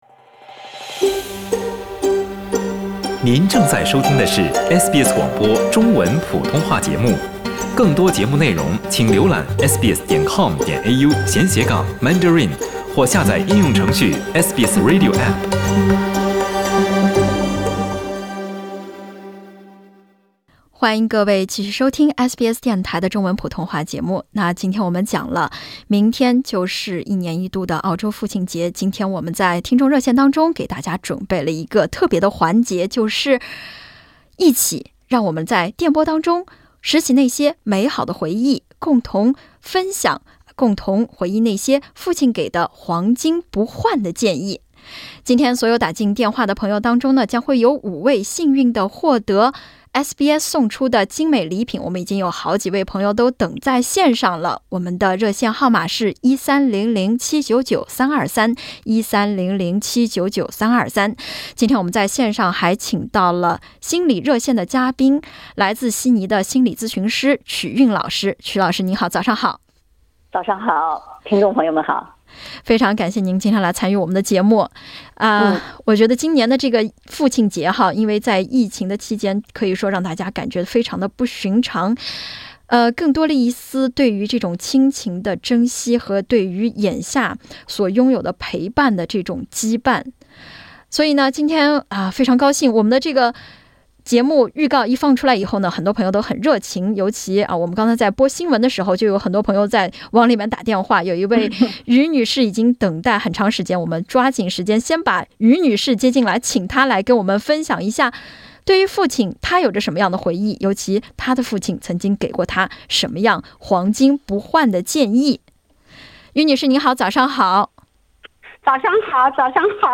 9月的第一个星期日是澳洲父亲节，SBS电台邀请听众朋友回忆父亲留给他们的条条金句，引发了一大波回忆热潮。